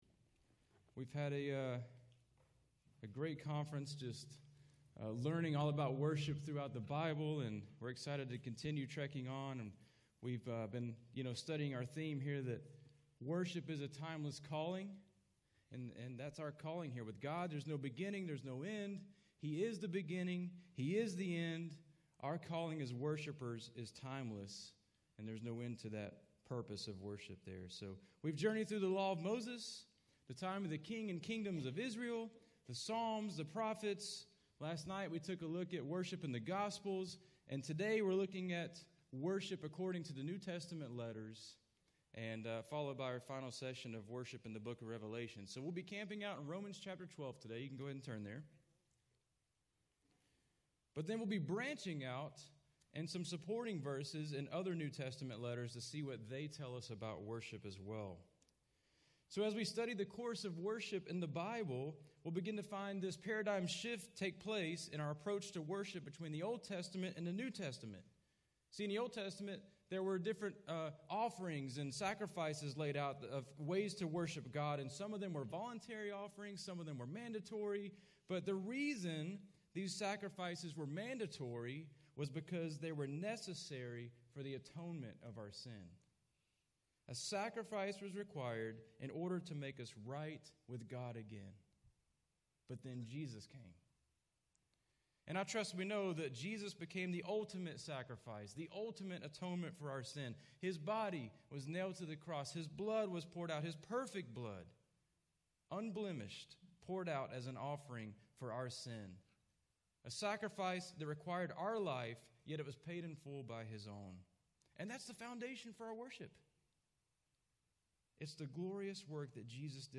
Home » Sermons » DSWC 2023 – Session 5